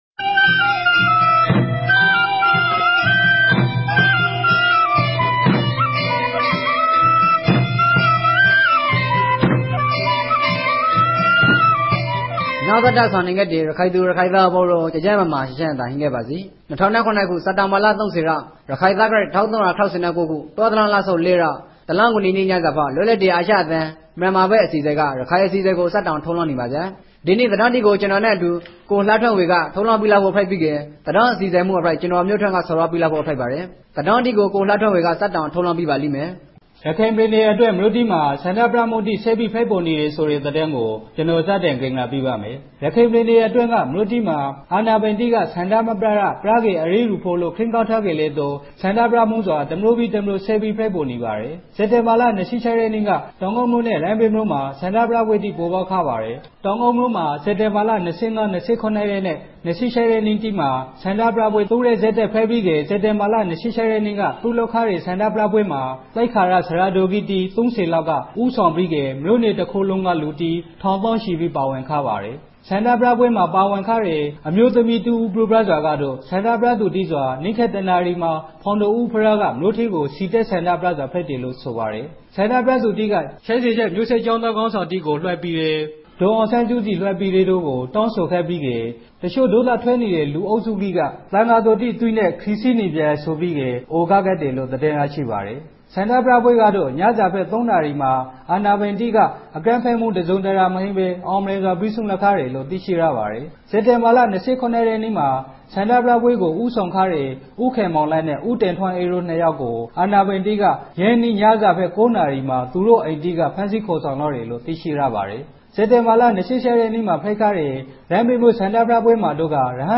ရခိုင်ဘာသာအသံလြင့်အစီအစဉ်မဵား